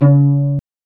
Index of /90_sSampleCDs/Roland LCDP13 String Sections/STR_Vcs Marc&Piz/STR_Vcs Pz.3 dry